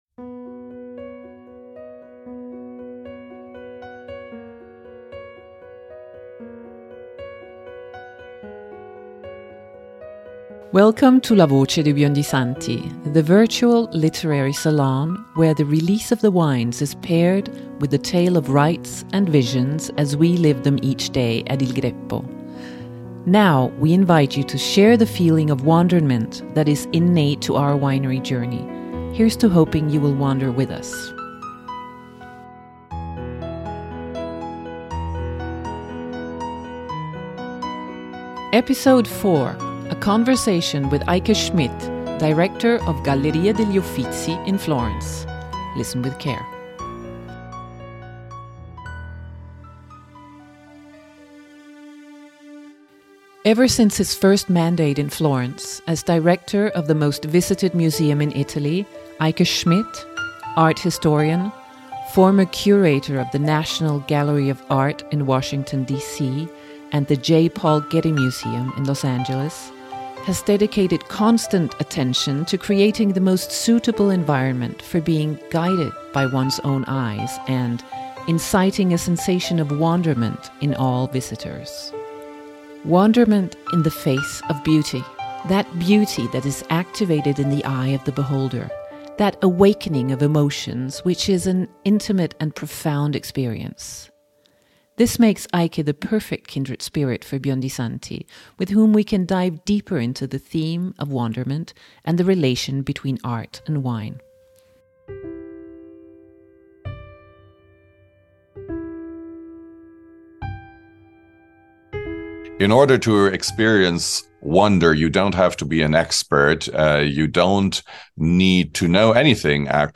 With Eike Schmidt, Director of the Uffizi Galleries in Florence.